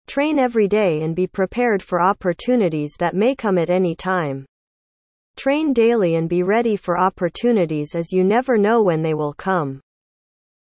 (Text-to-Speech by Sound of Text, using the engine from Google Translate)